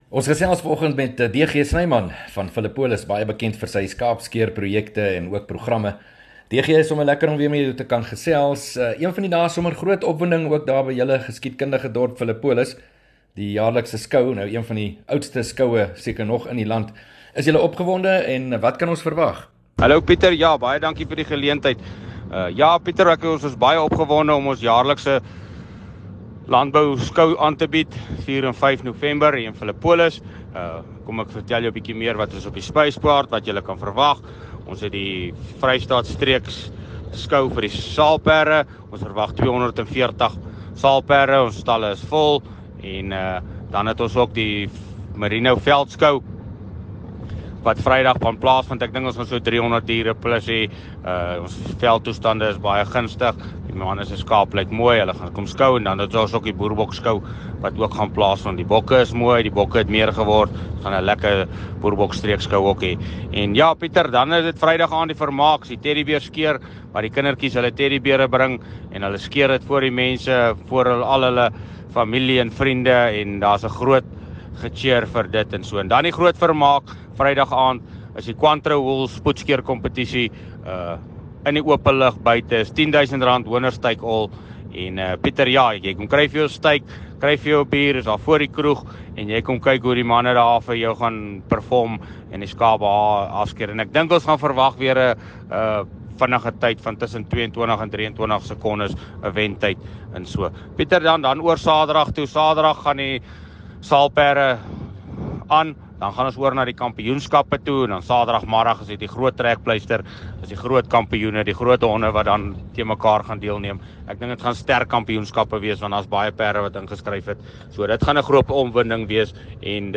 gesels met ‘n jongboer